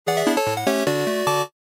Звуки завершения, эффект
Звук после титров джаз